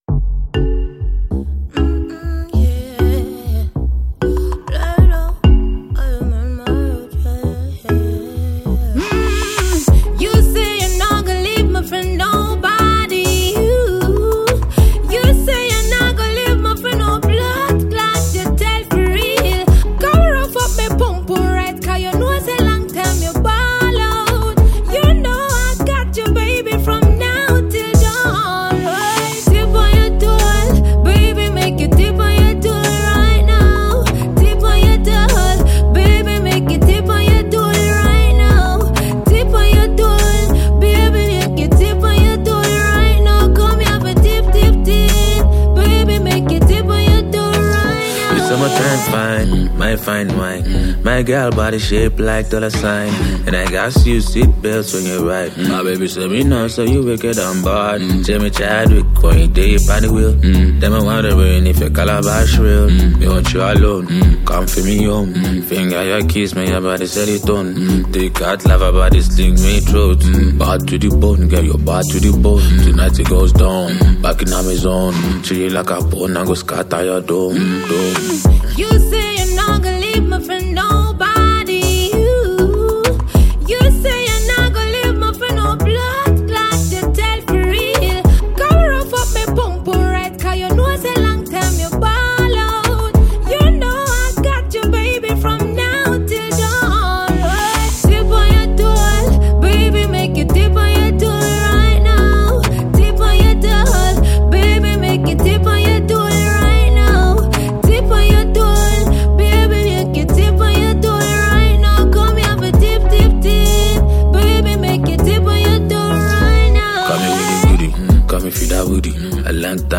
All the song are made of pop sounds .